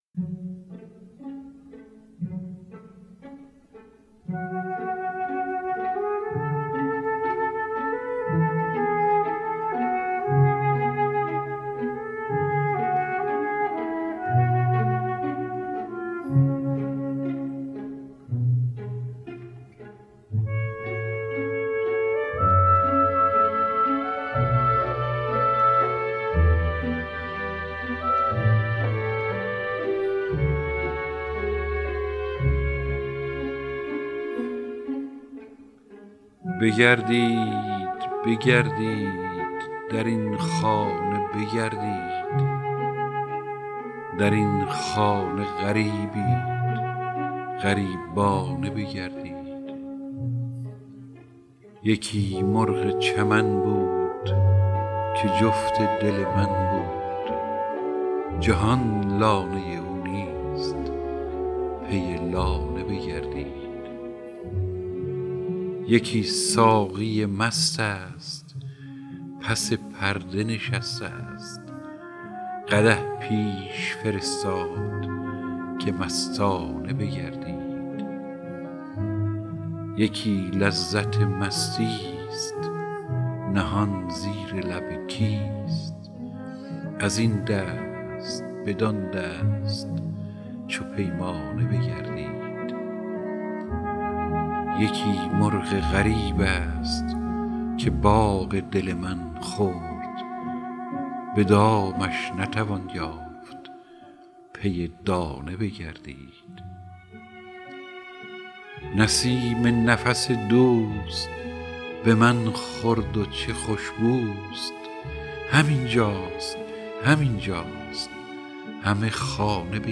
دانلود دکلمه یگانه با صدای هوشنگ ابتهاج
گوینده :   [هوشنگ ابتهاج]